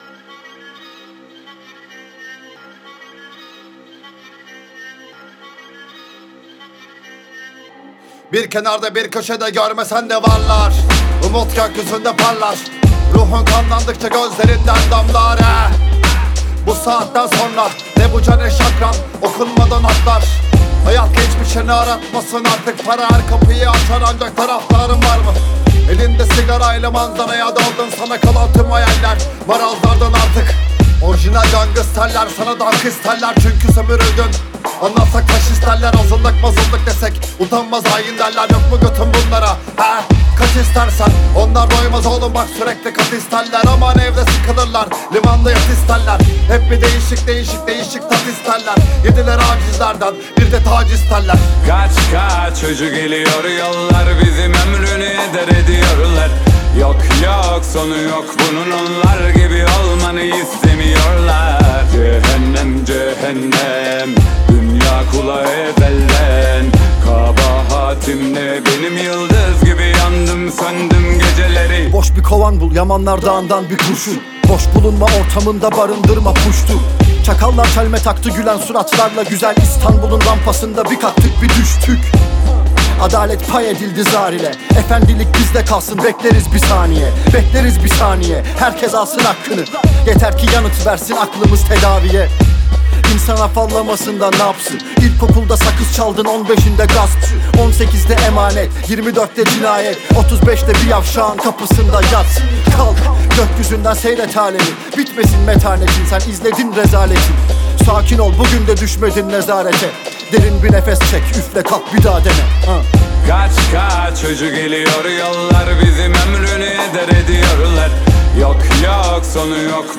آهنگ ترکی بیر کناردا بیر کوشده گورمسنده وارلار